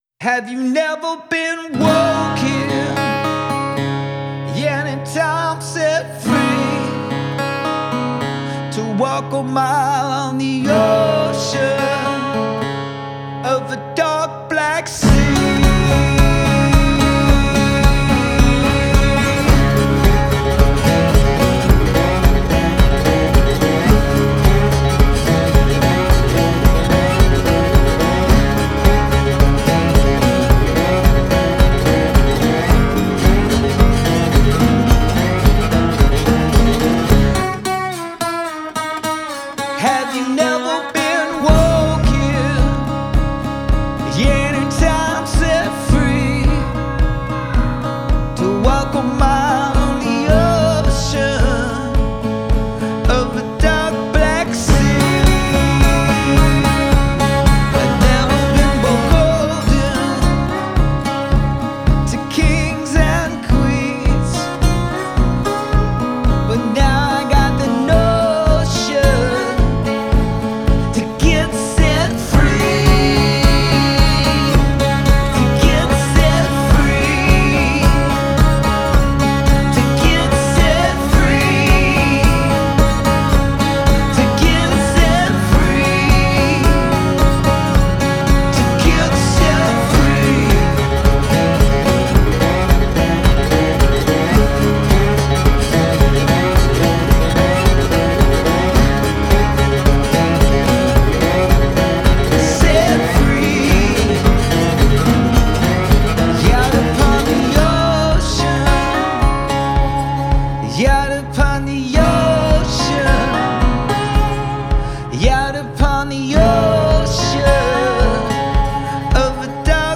Delta blues